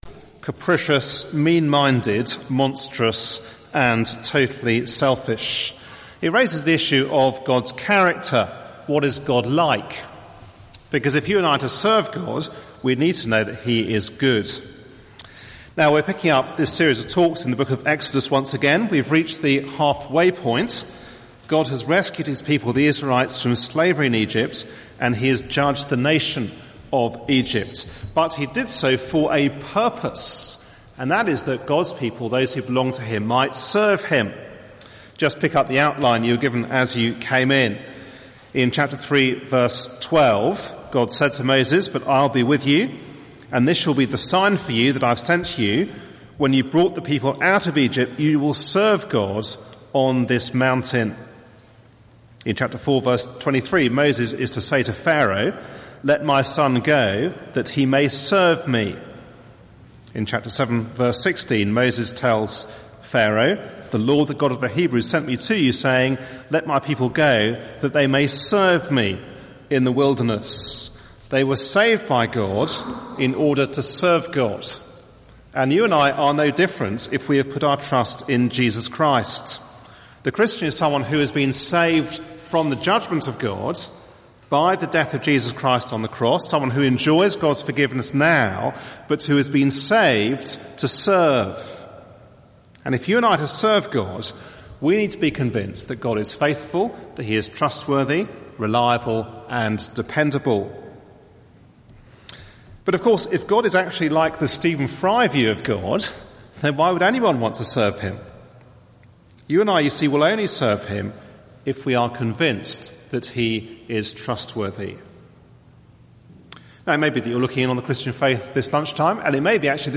The God you're looking for/ The God who brings joy Exodus 18 RECORDED AT THE ALDERSGATE TALKS ()
given at a Wednesday meeting